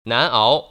[nán’áo] 난아오